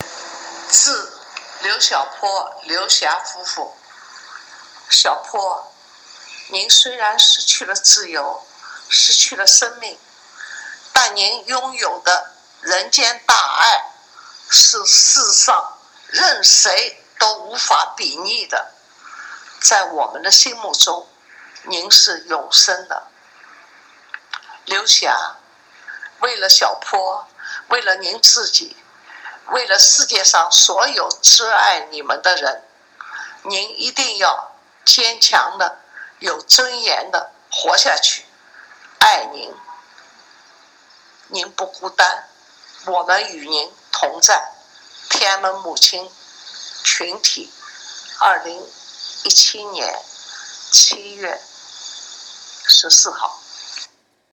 六四难属群体天安门母亲代表人物丁子霖以录音方式发表悼词，怀念日前在沈阳逝世的著名异议人士刘晓波，赞扬刘晓波拥有的人间大爱无可比拟，并安慰鼓励刘晓波遗孀刘霞坚强而有尊严地活下去。